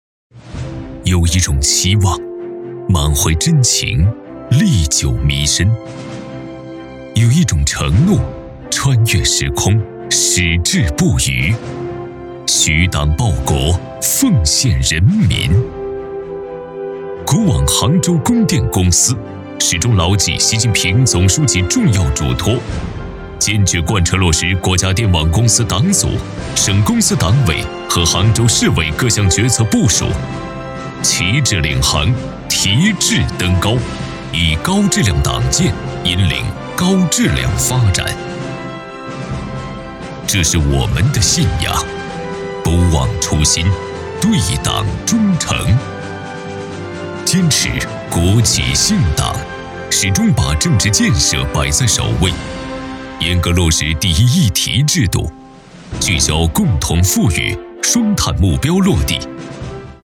15 男国211_专题_企业_国网杭州供电公司_大气 男国211
男国211_专题_企业_国网杭州供电公司_大气.mp3